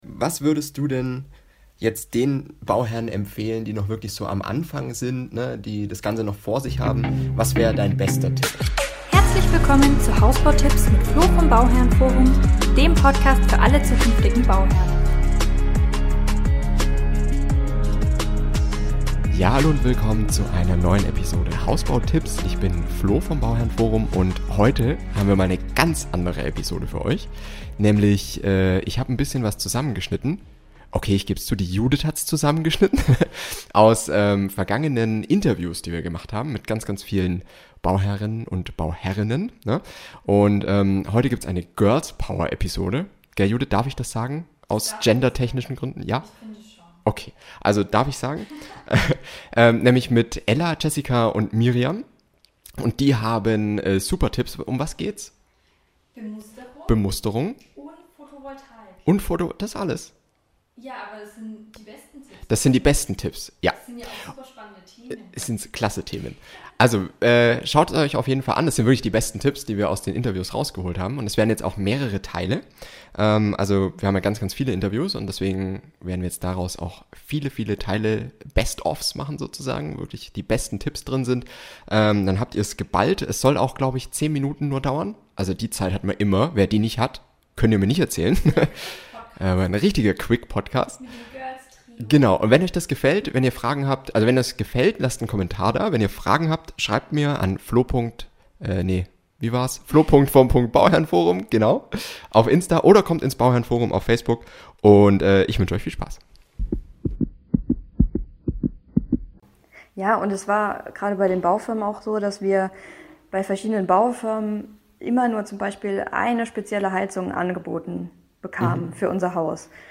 Bemusterung und PV Anlage aus den Interviews mit 3 Bauherrinnen